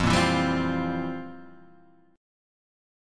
F Major 7th Suspended 2nd Suspended 4th